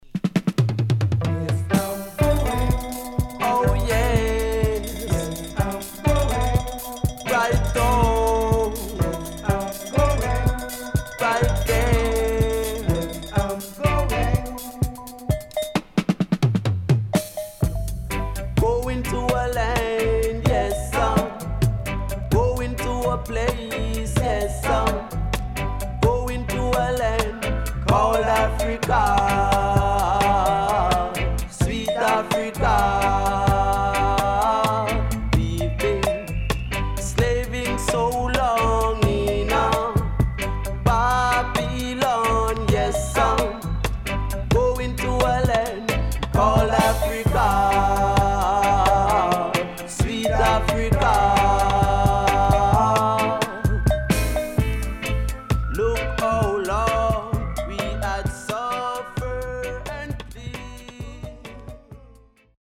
HOME > Back Order [VINTAGE DISCO45]  >  KILLER & DEEP
SIDE A:少しチリノイズ入りますが良好です。